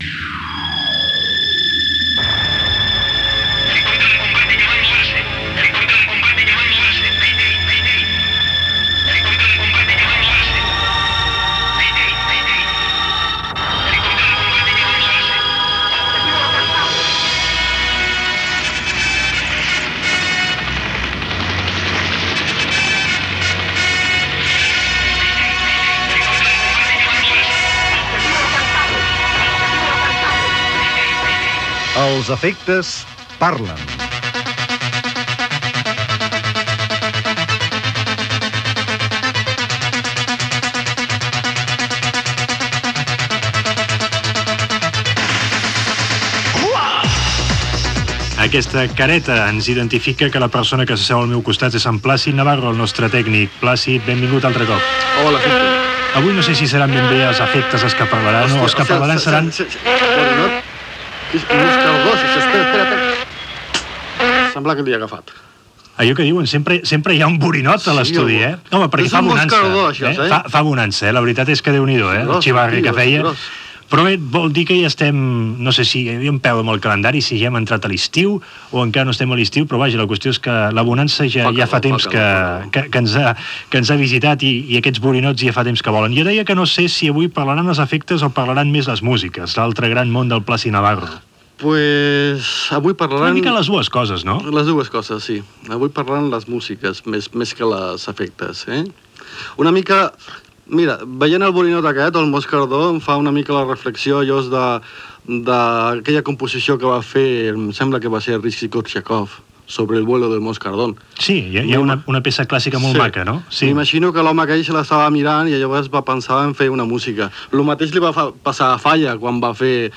Muntatge sonor sobre l'aigua.
Divulgació